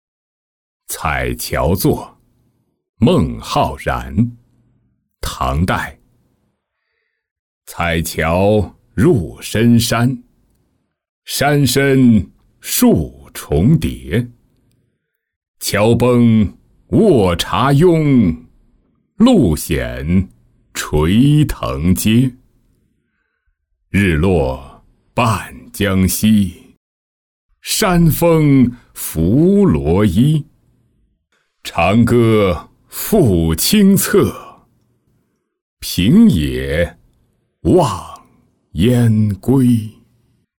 采樵作-音频朗读